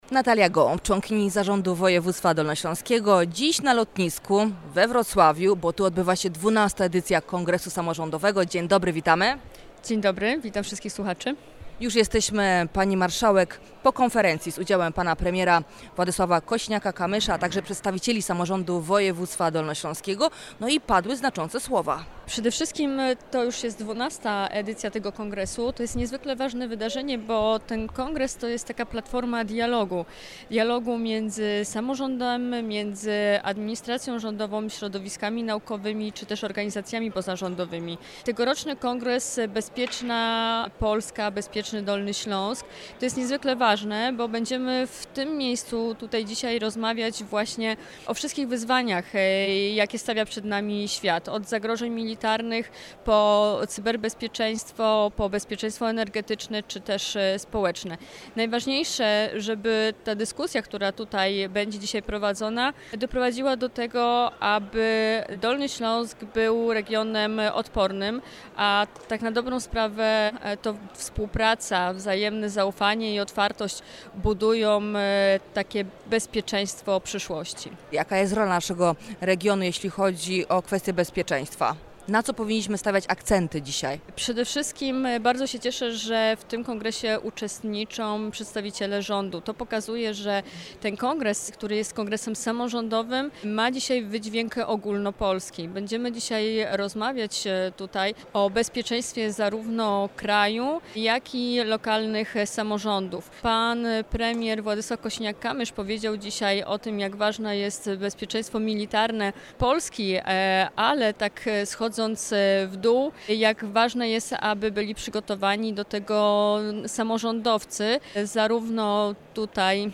Natalią Gołąb – członkini Zarządu Województwa Dolnośląskiego: